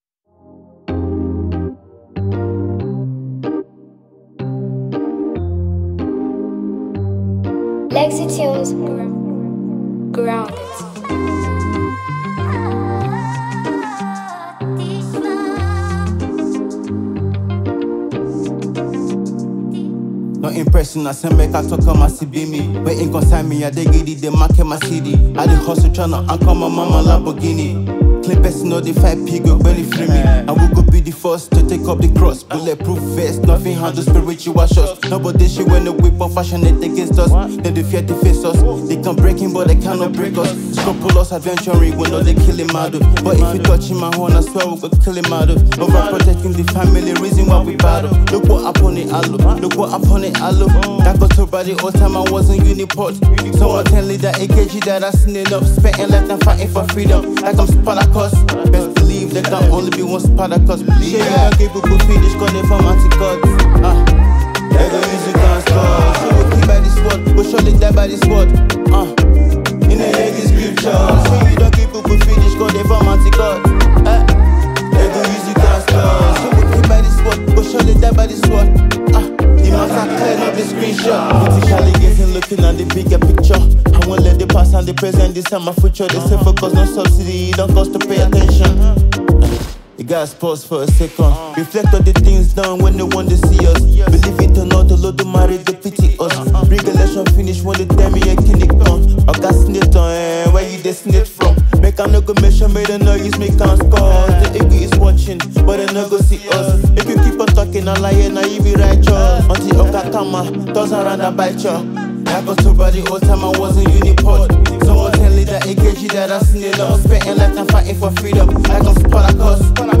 Nigerian talented rapper and songwriter